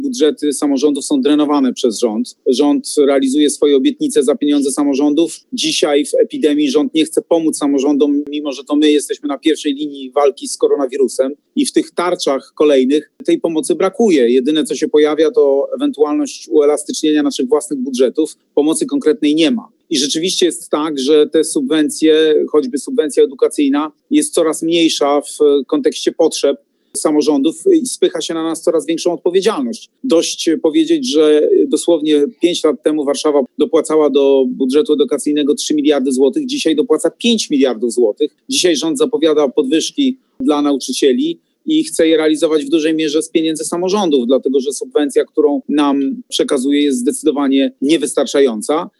Rozmawialiśmy z Rafałem Trzaskowskim.